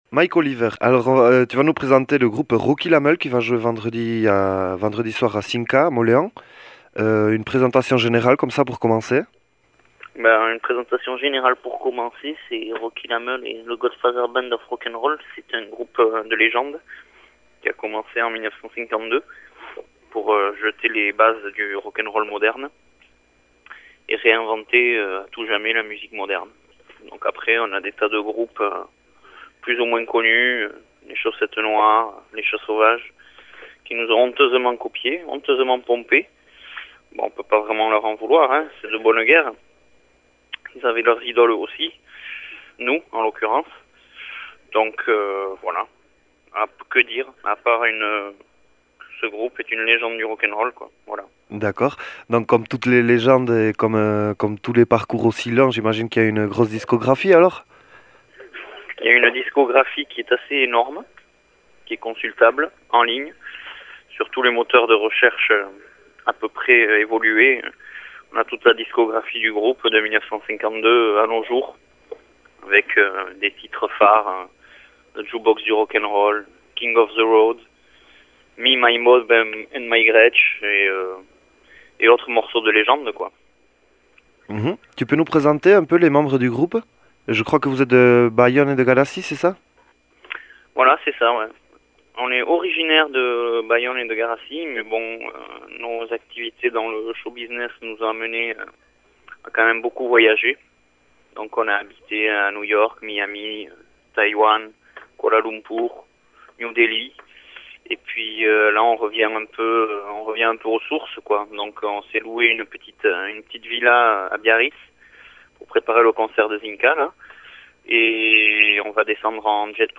Alkarrizketa behatzen badüzüe laster ohartüko zide ez direla seriuski ari.